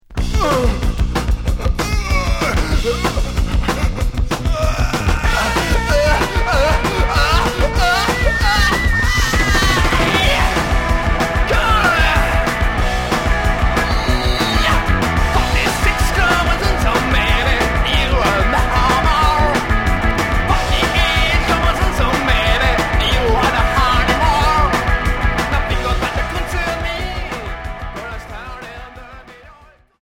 Punk rock stoogien